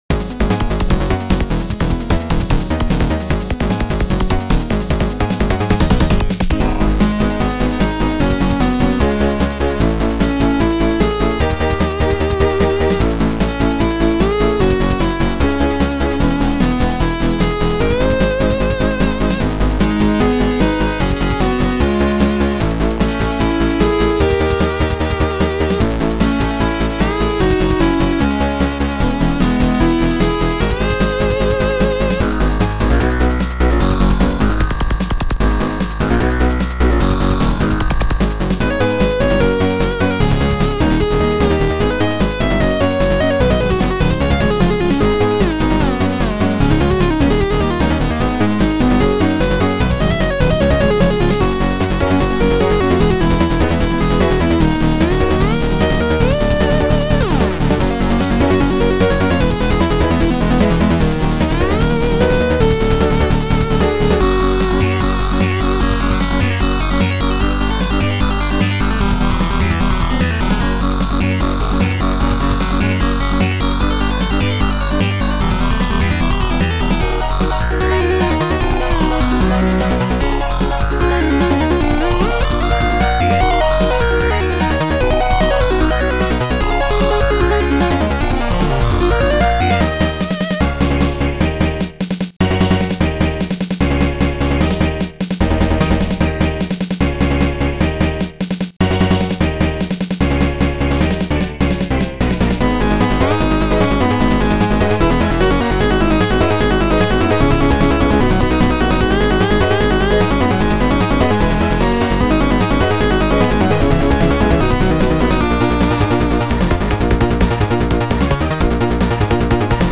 Uptempo, energetic, 4-channel army game style Amiga game chiptune.